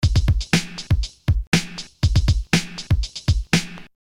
三重低音120
Tag: 120 bpm Funk Loops Drum Loops 689.25 KB wav Key : Unknown